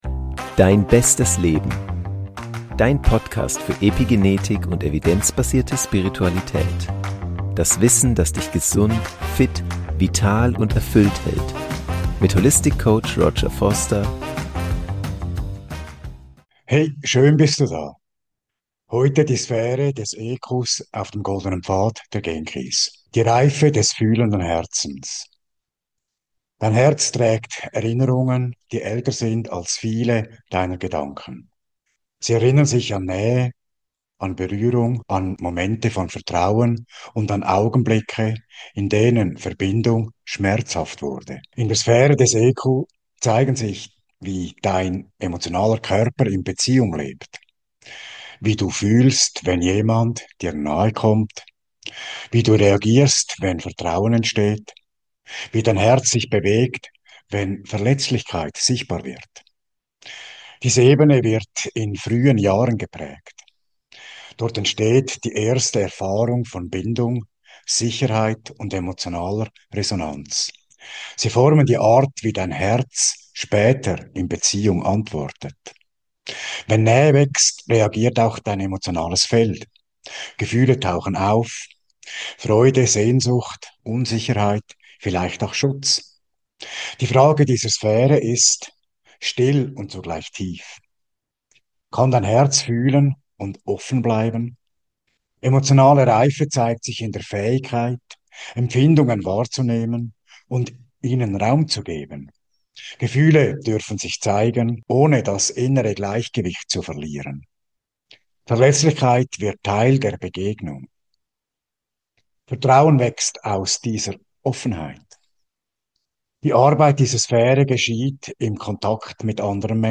Meditation zur Sphäre des EQ
Sanft, ruhig und mit genügend Raum für innere